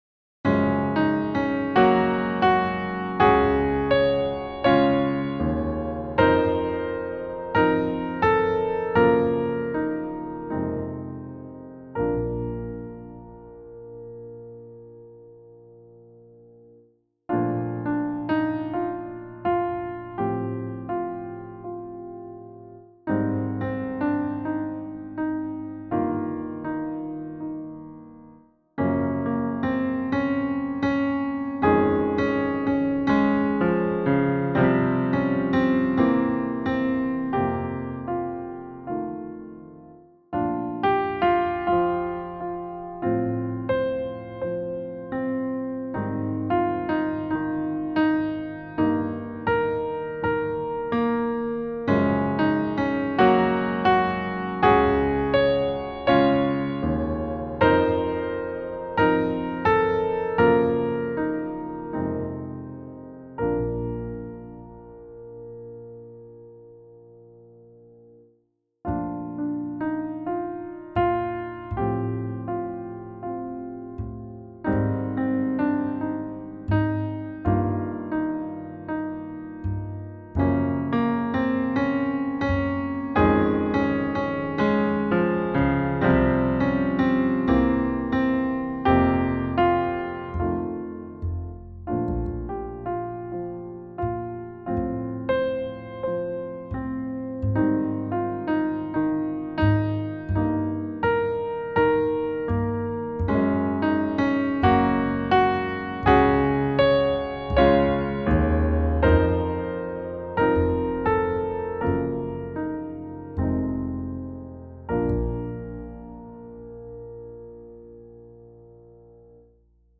Click here to download the backing track.